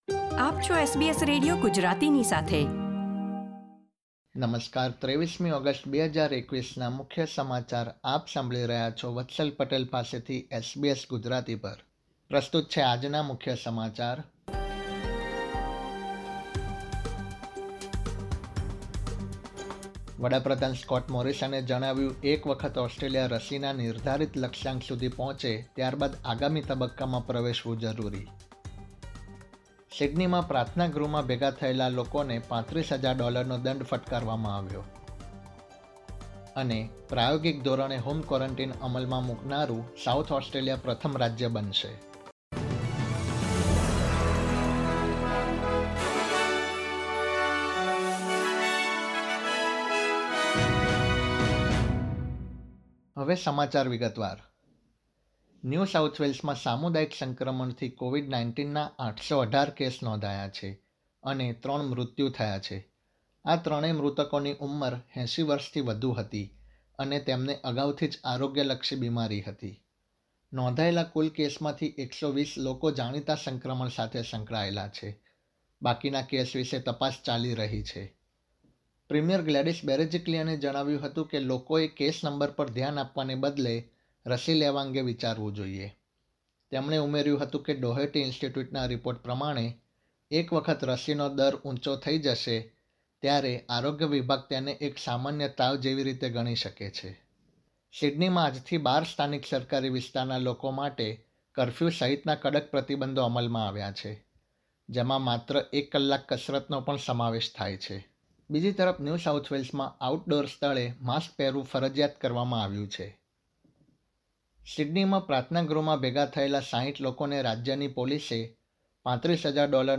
SBS Gujarati News Bulletin 23 August 2021